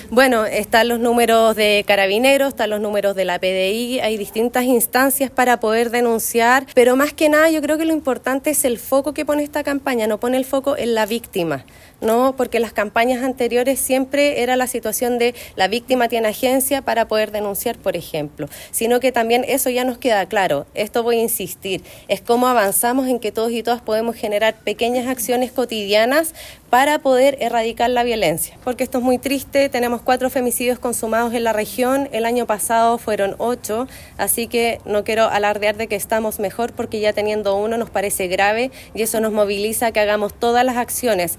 La actividad se realizó en la Sala de Sesiones del Municipio y contó con la participación de autoridades regionales, provinciales y locales; además de dirigentas sociales.
Uno de los focos de la campaña es poner en énfasis el rol de la comunidad, en cuanto a las acciones cotidianas que se pueden realizar para que se produzca un cambio en la forma de dar visibilidad a la violencia, como explicó la Seremi de la Mujer y Equidad de Género.